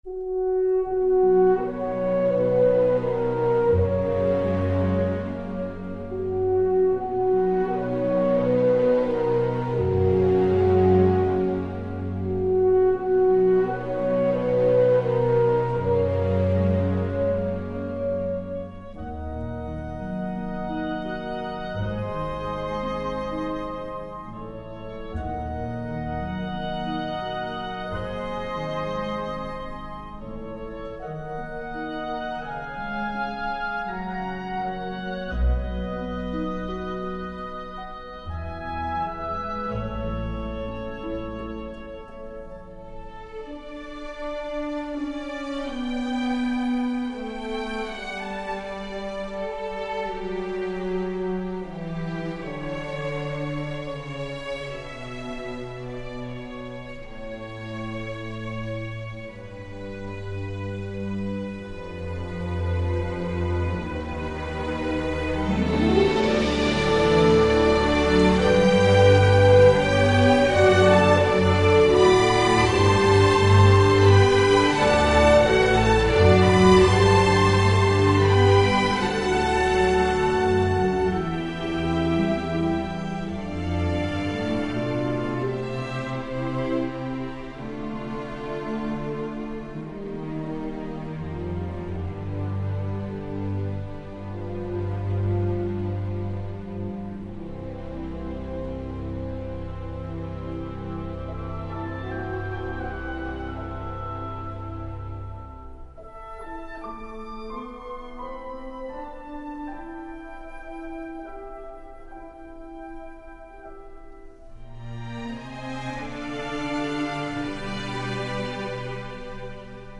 永远的经典，有一种忧伤的感觉。一种悲壮的曲调，让人难以忘怀。